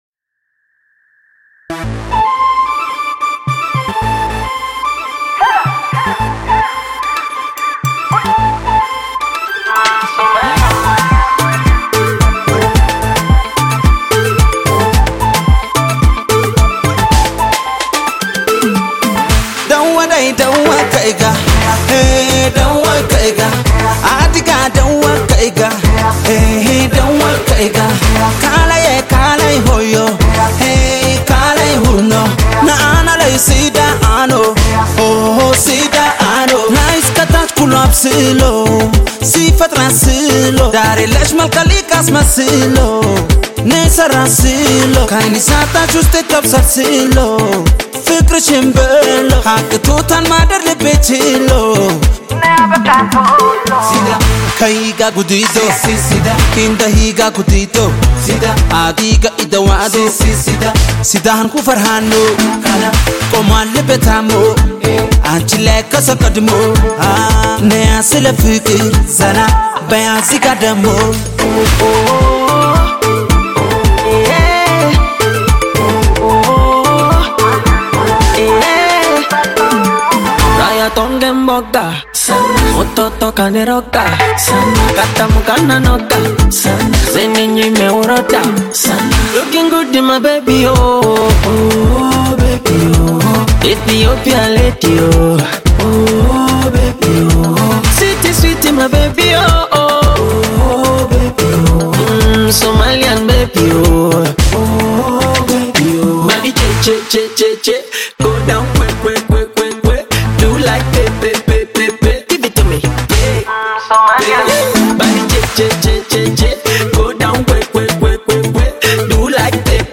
Ethiopian modern afrobeat singer
African Music